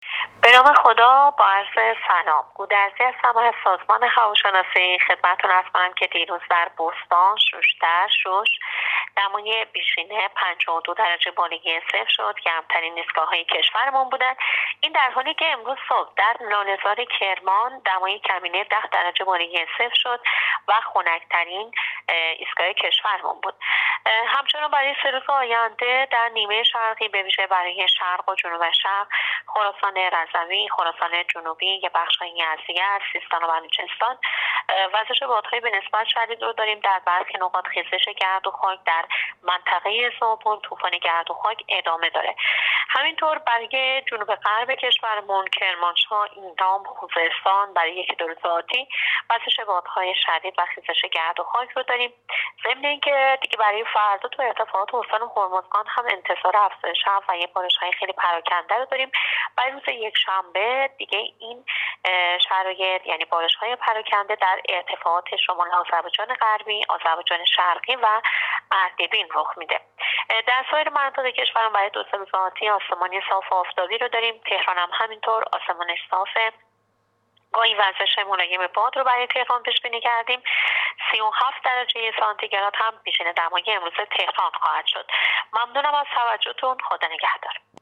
در گفتگو با راديو اينترنتی پايگاه خبری آخرين وضعيت هوا را تشریح کرد.